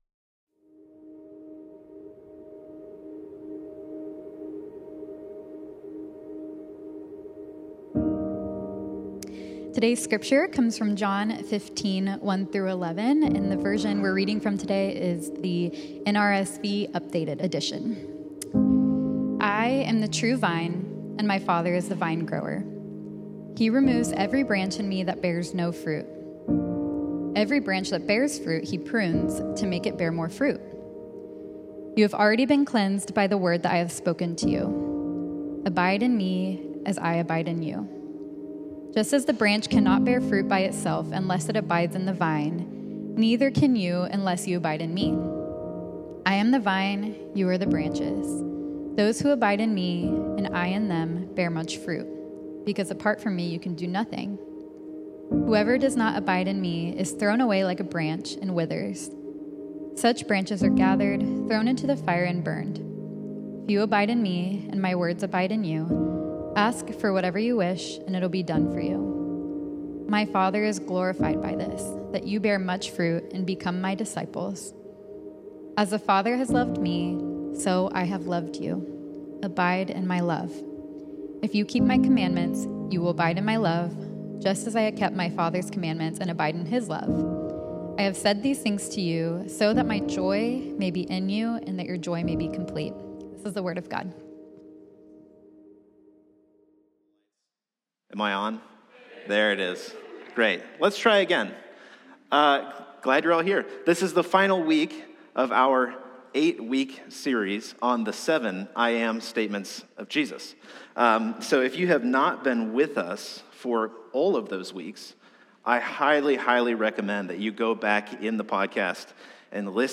Sermons | Central Vineyard Church